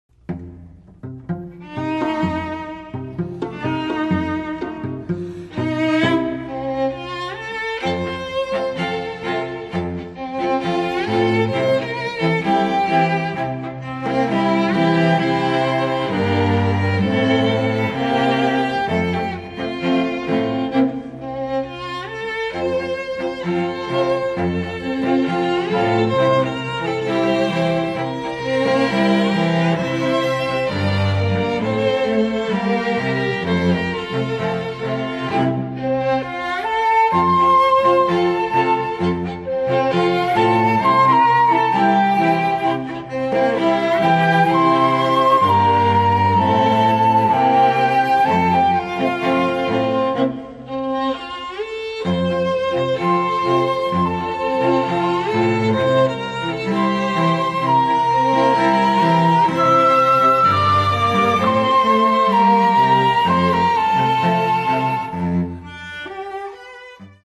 (Flute, Violin, Viola and Cello)
or STRING QUARTET
(Two Violins, Viola and Cello)
MIDI
(Traditional Polish Tango)